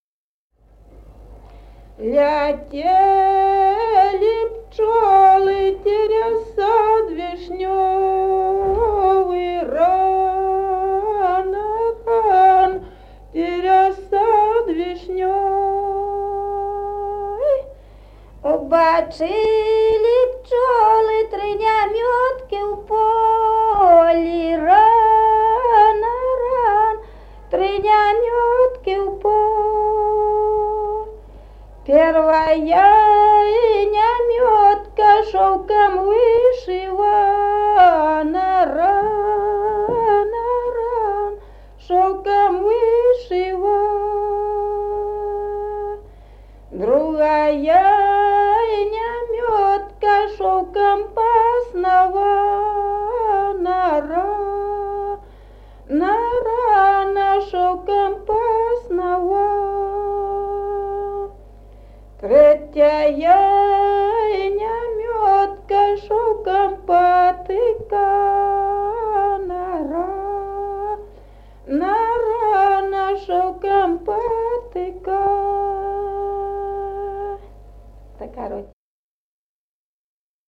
Народные песни Стародубского района «Летели пчёлы», гряная.
1953 г., с. Мохоновка.